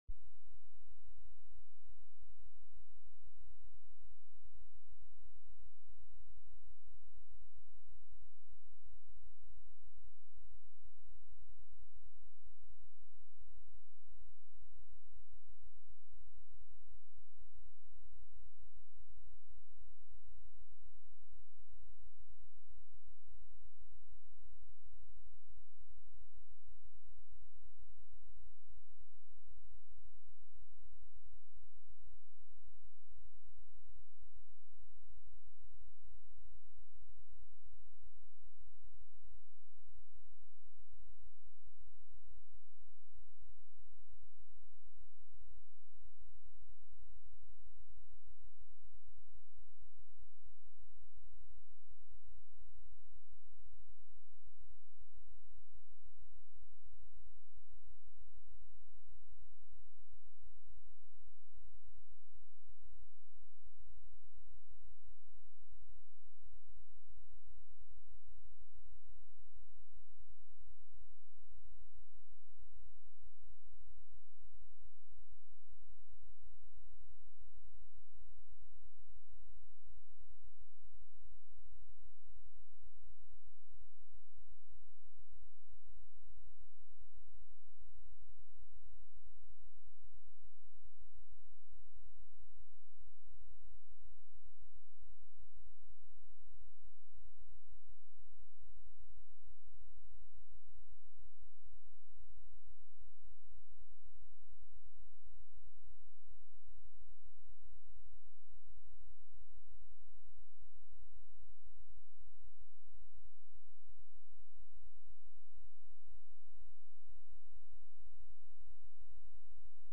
From Series: "Sunday Worship"
Sunday-Service-10-8-23.mp3